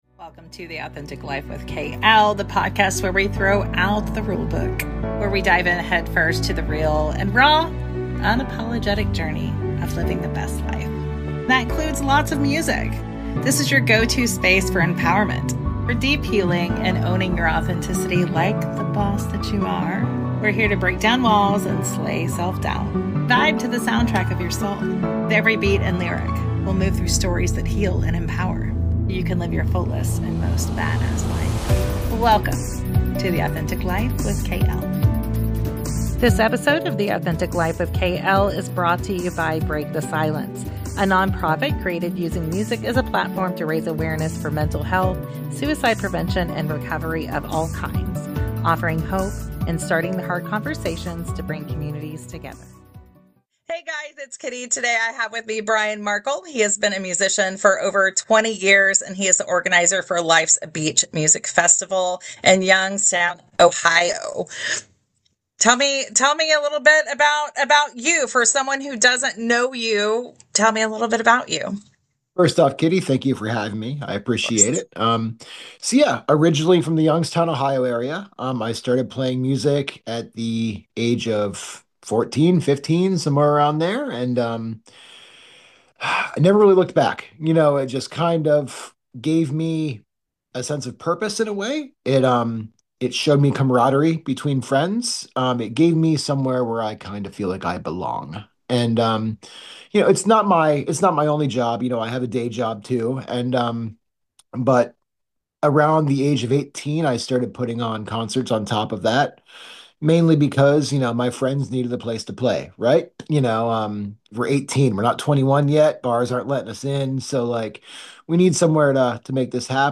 Today’s episode is one of those conversations that reminds you why music matters in the first place.